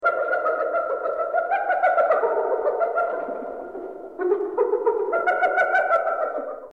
На этой странице собраны разнообразные звуки гиены: от характерного смеха до агрессивного рычания.
Скрытый звук смеха гиены вдали